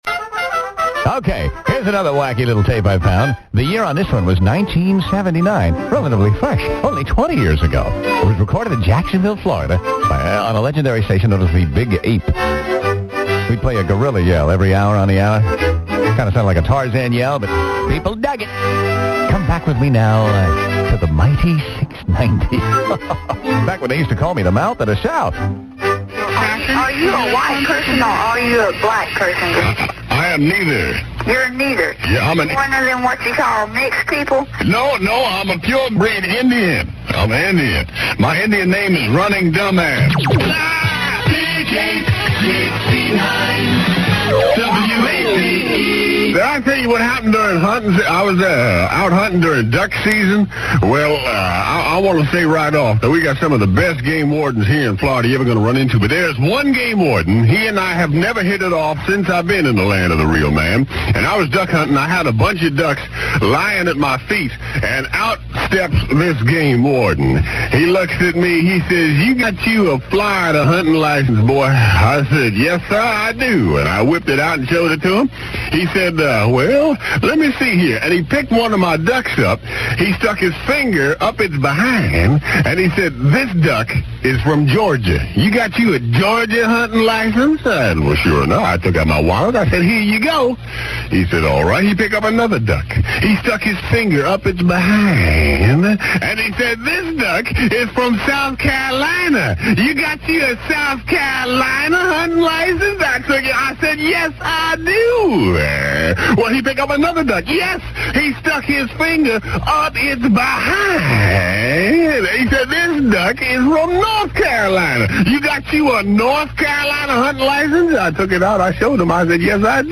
WAPE 1979 aircheck